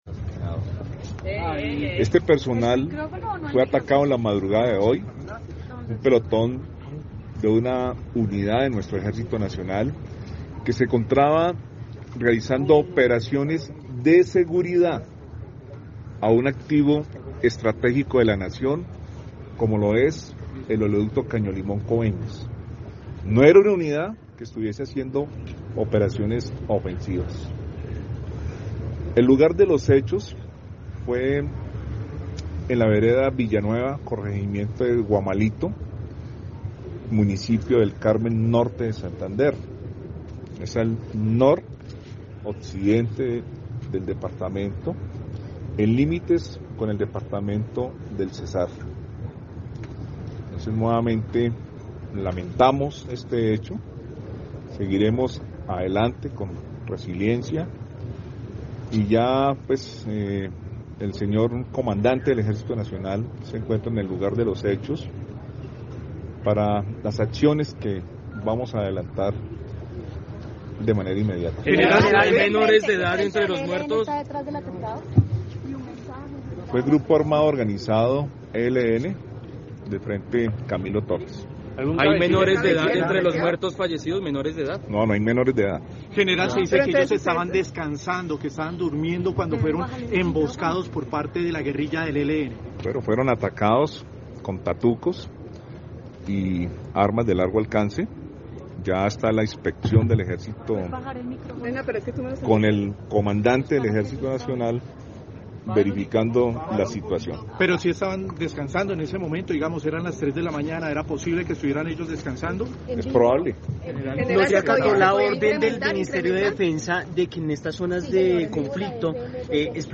Declaraciones del general Helder Fernán Giraldo Bonilla, Comandante Fuerzas Militares